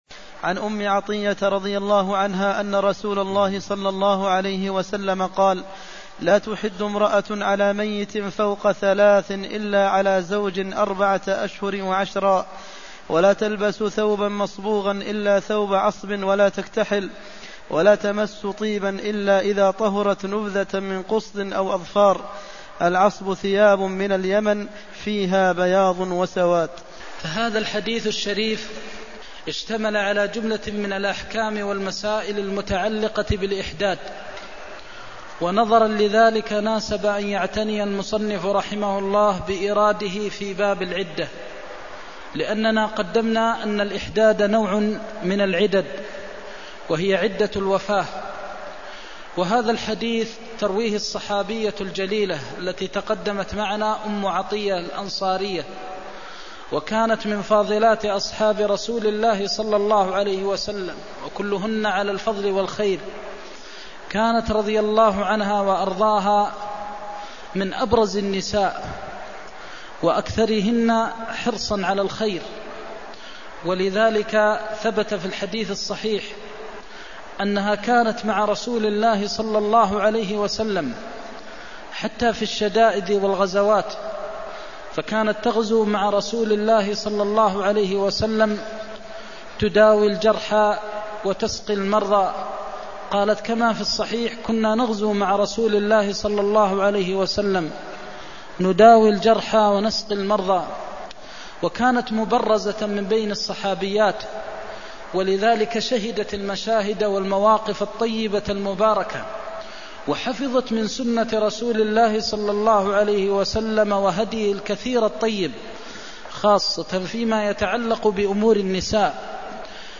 المكان: المسجد النبوي الشيخ: فضيلة الشيخ د. محمد بن محمد المختار فضيلة الشيخ د. محمد بن محمد المختار نهيه عن الزينة للحادة (304)